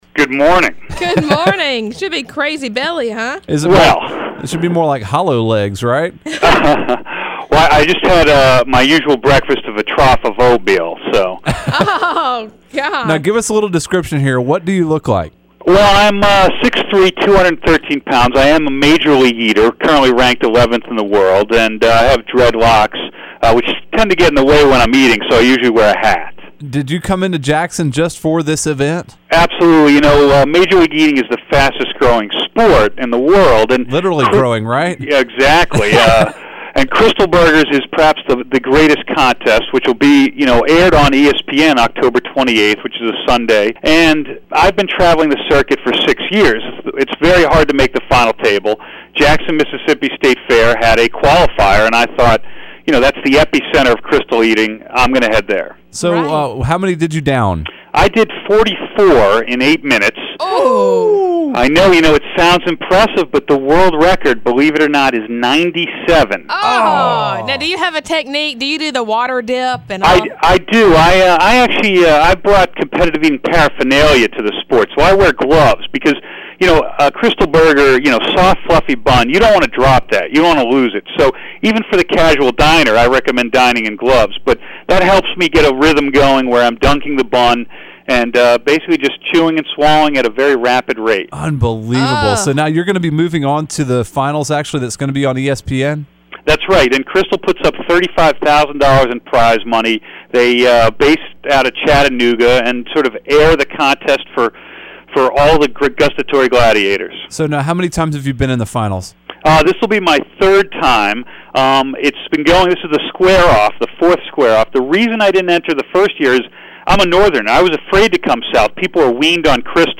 Jackson Krystal qualifier video on a Mississippi radio staion.